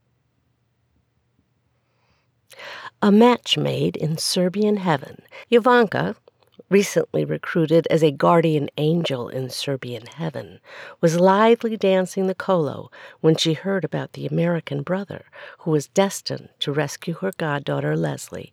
It takes a little of the harshness and lip smacks out in addition to the other tools.
Only the second clip has DeCrisper added.